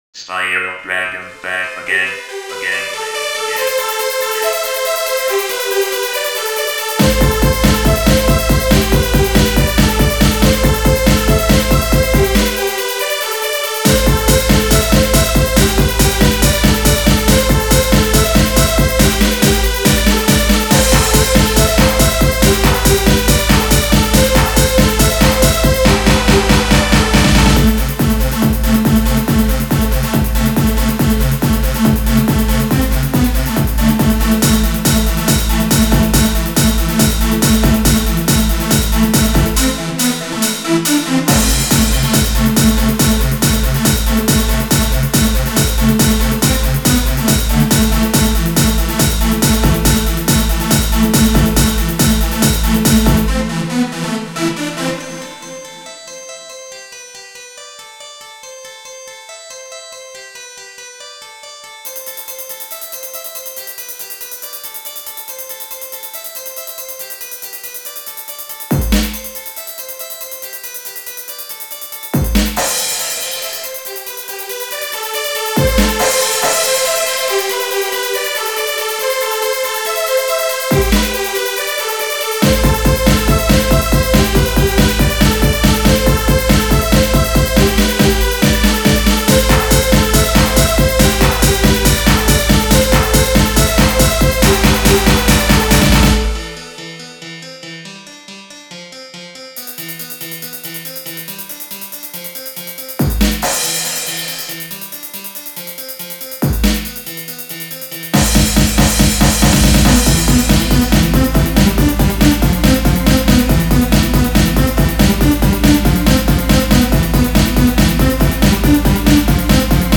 This is one of my favorite tencho songs